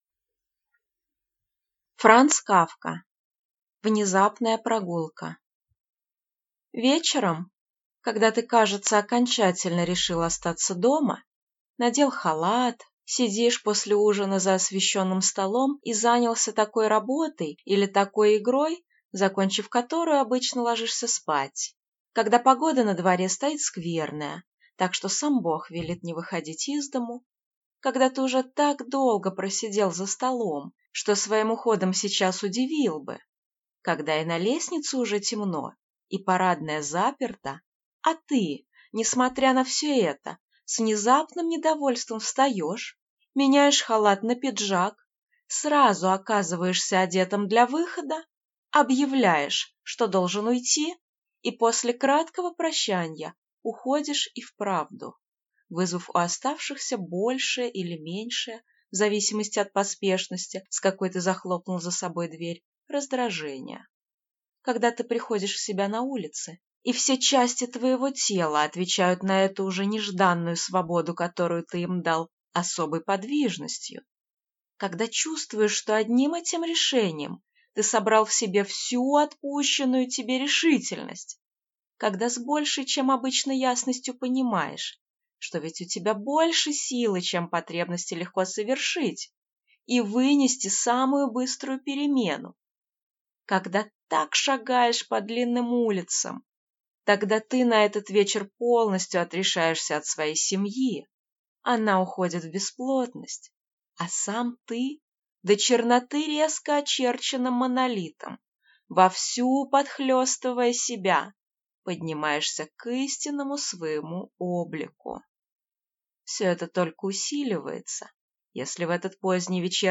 Аудиокнига Внезапная прогулка | Библиотека аудиокниг